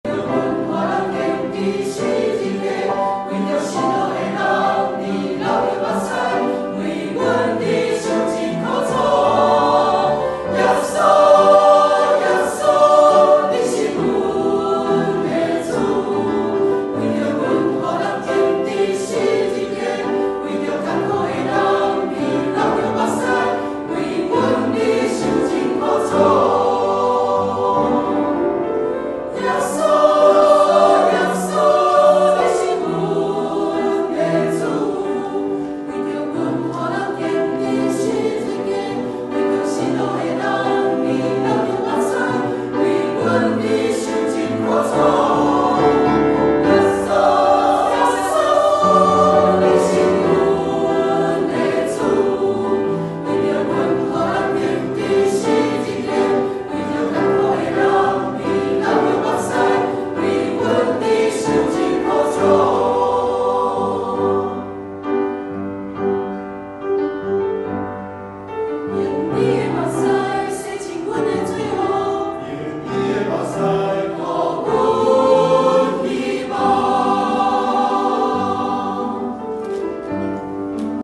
三年疫情，圣歌队第一次正式献唱